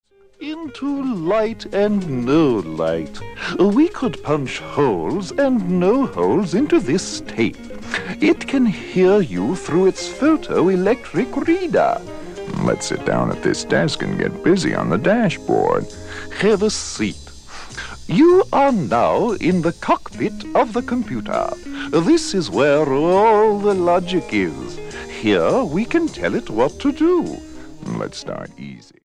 STYLE: Jesus Music
baritone speaking voice
fuzz guitars, groovy sounds and a sprinkling of avant garde